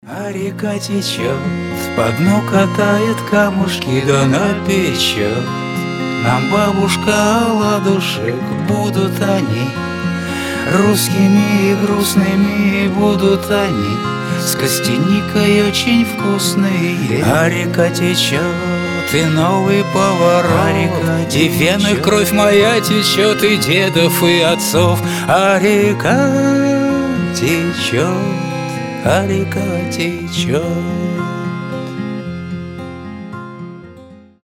• Качество: 320, Stereo
душевные
спокойные
из фильмов